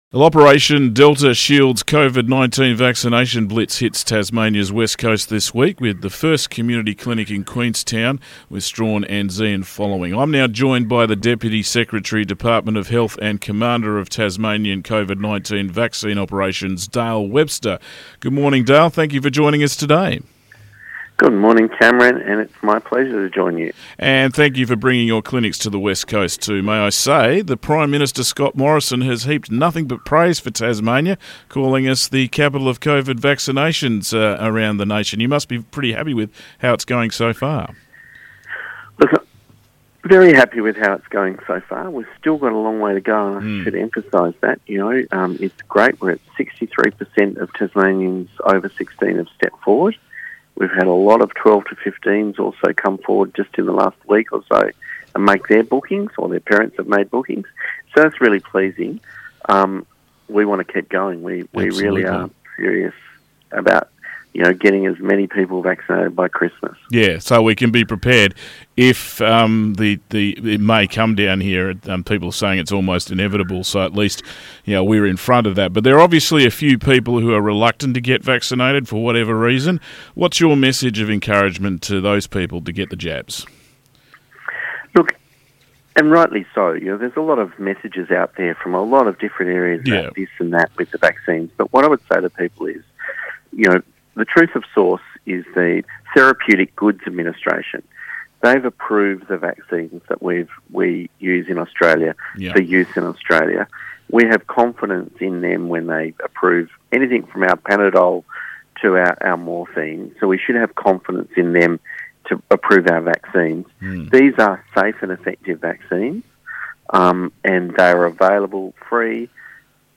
Interview with Dale Webster from Tas Health